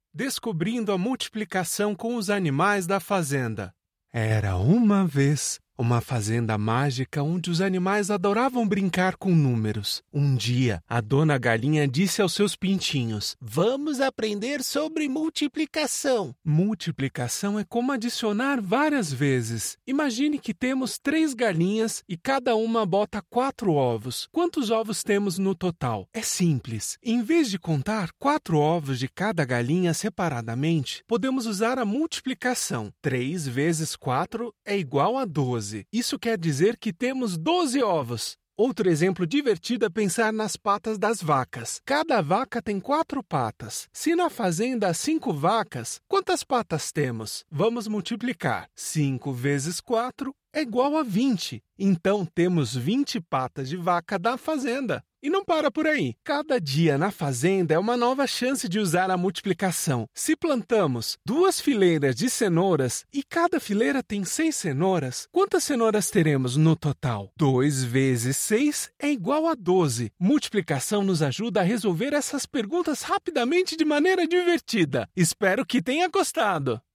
Animation
Ma configuration de studio à la pointe de la technologie garantit une qualité sonore exceptionnelle pour chaque projet.
Cabine acoustique parfaite